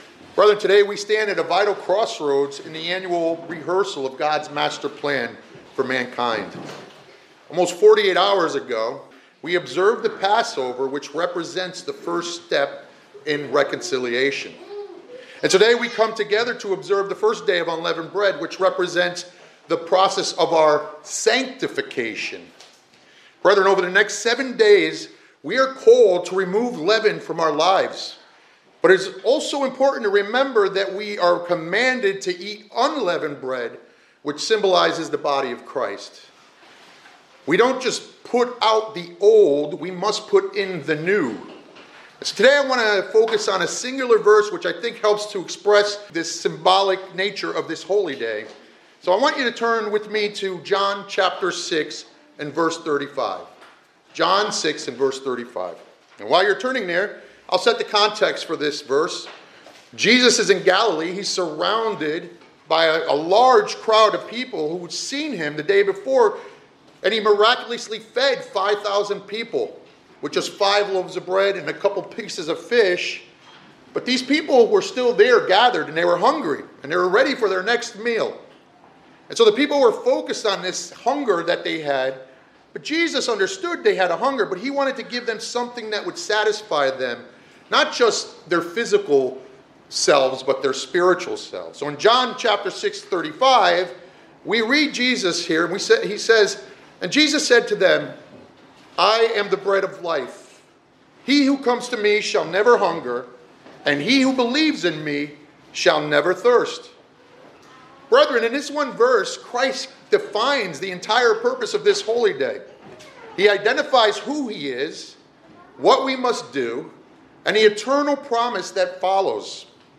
This sermonette focuses on the First Day of Unleavened Bread by explaining how Jesus Christ’s statement, “I am the bread of life” in John 6:35 reveals the deeper spiritual meaning of this Holy Day. The message emphasizes that while the crowd in Galilee sought physical bread after being fed, Christ pointed them to a deeper need, lasting spiritual nourishment that only He can provide.